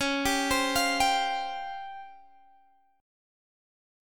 Listen to DbM7b5 strummed